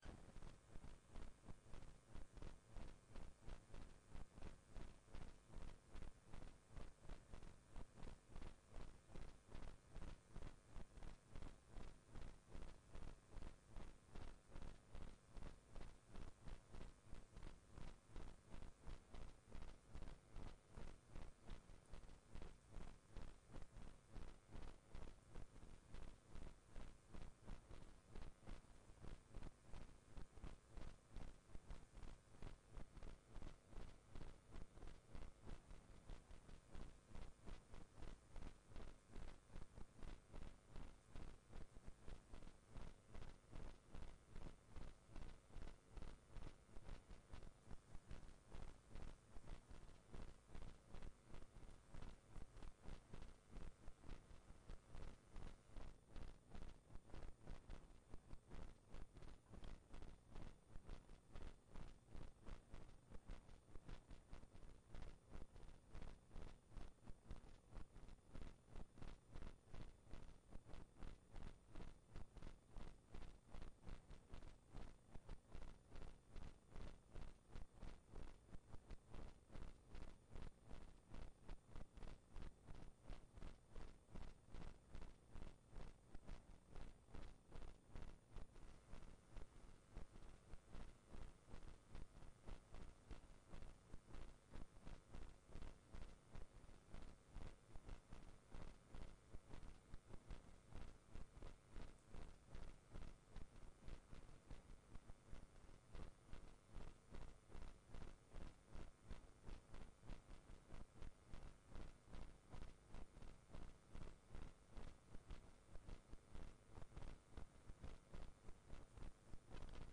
Forelesning 5.2.2020